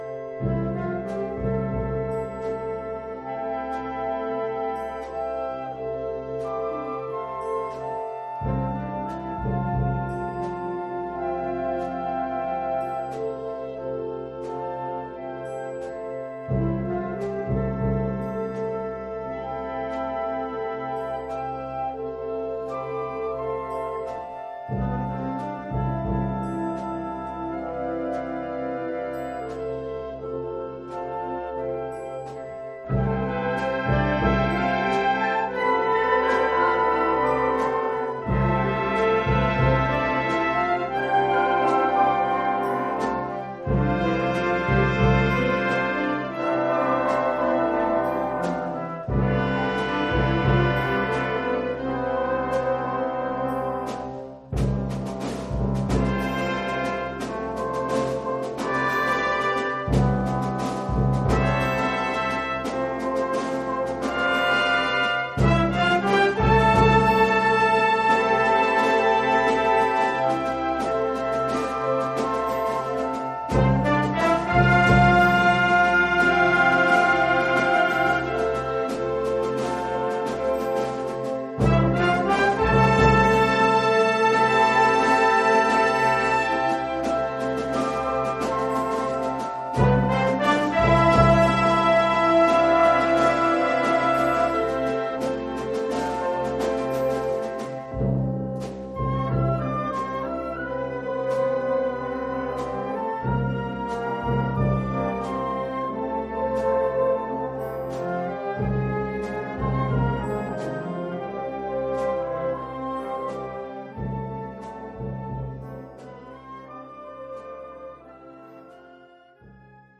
Collection : Orchestre d'harmonie
Suite pour orchestre d’harmonie.